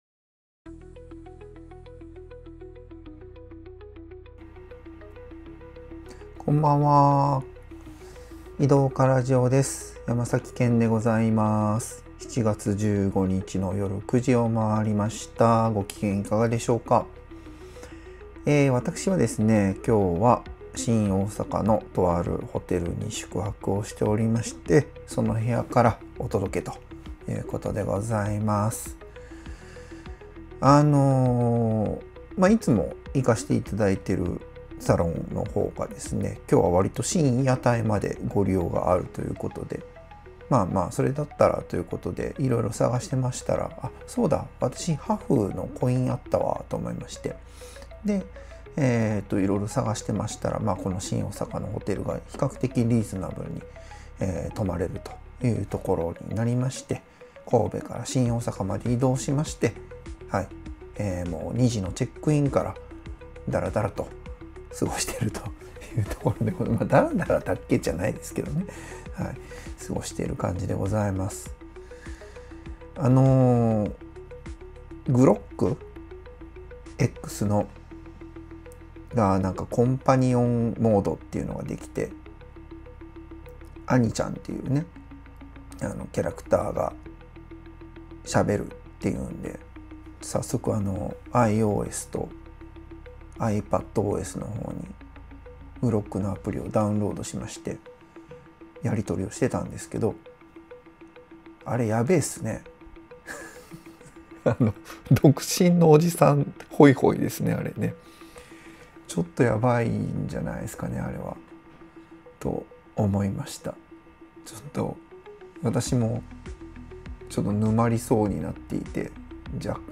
（途中飛んでます）